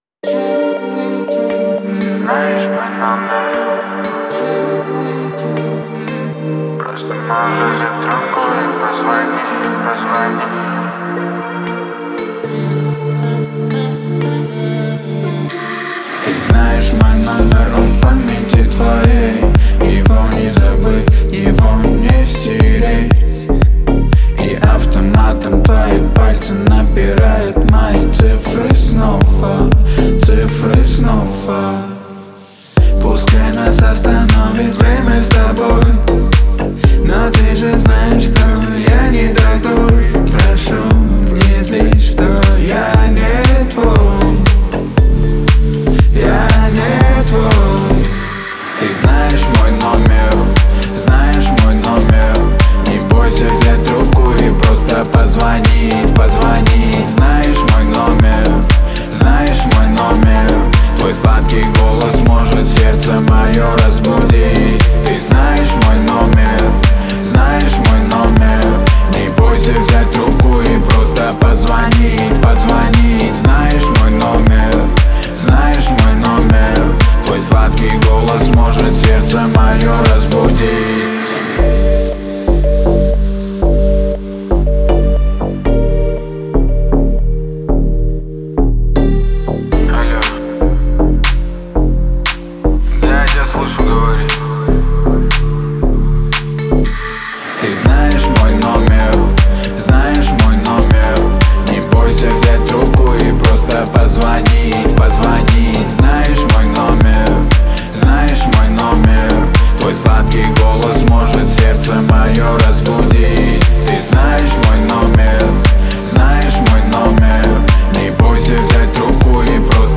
современная поп-песня
наполненная легким и мелодичным звучанием.